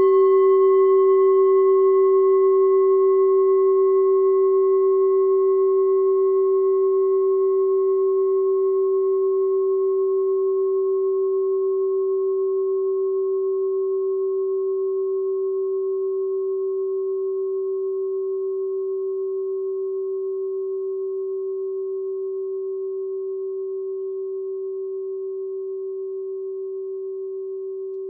Kleine Klangschale Nr.20
Sie ist neu und ist gezielt nach altem 7-Metalle-Rezept in Handarbeit gezogen und gehämmert worden.
(Ermittelt mit dem Minifilzklöppel)
Der Jahreston(OM) klingt bei 136,10 Hertz und in den Oktaven ober- und unterhalb. In unserer Tonleiter ist das nahe beim "Cis".
kleine-klangschale-20.wav